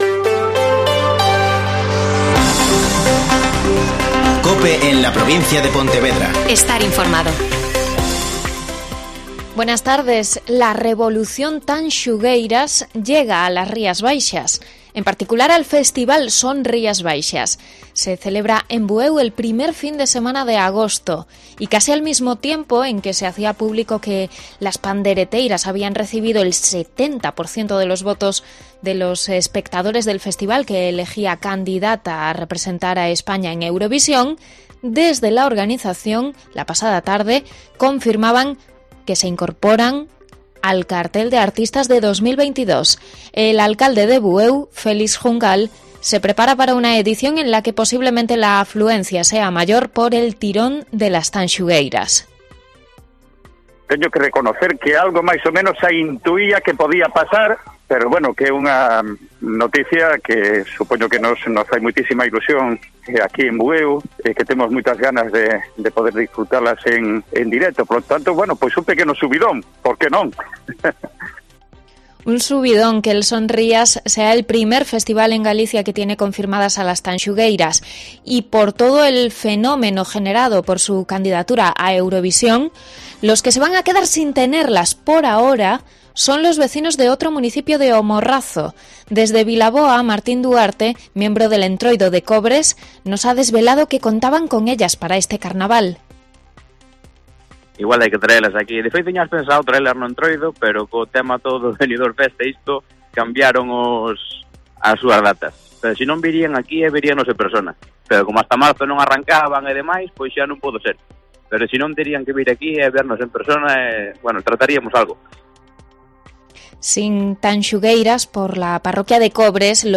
Mediodía COPE en la Provincia de Pontevedra (Informativo 14:20h.)